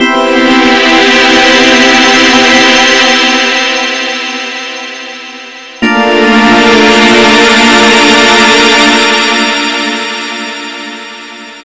05 Stringio G 165bpm.wav